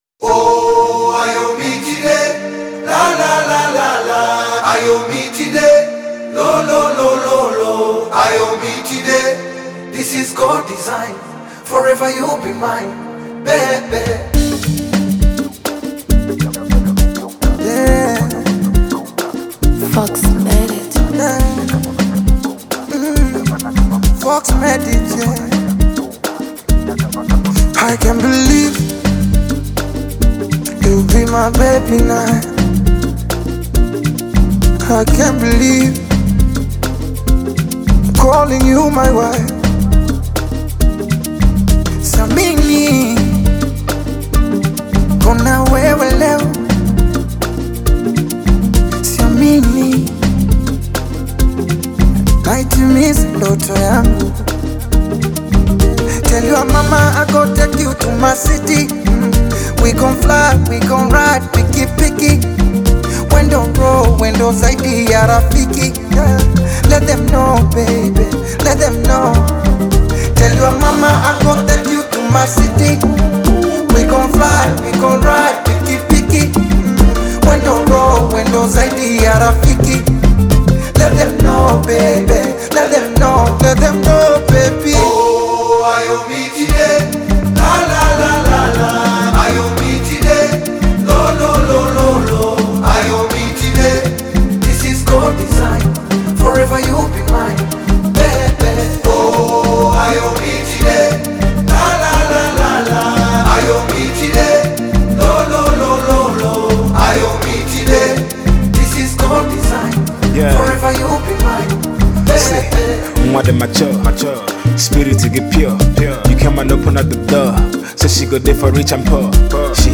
R&B with African beats